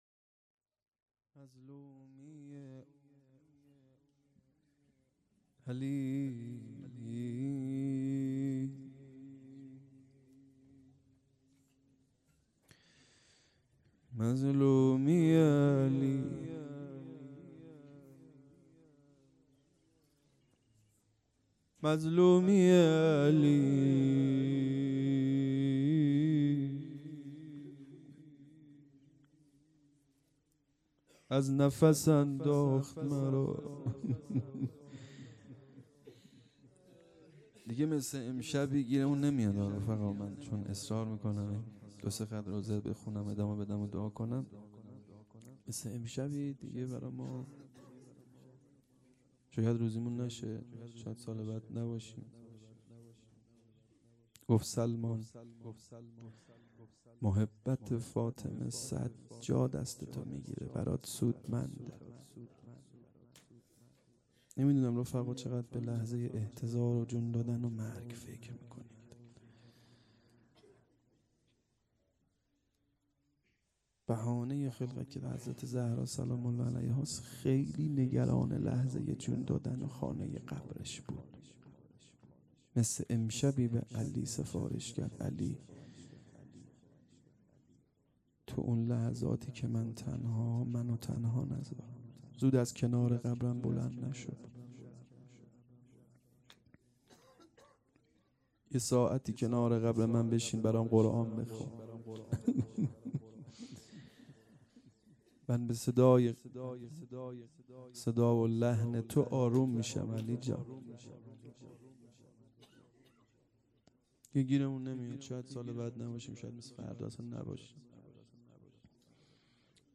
هيأت یاس علقمه سلام الله علیها